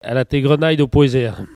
Langue Maraîchin
Patois - archives
Catégorie Locution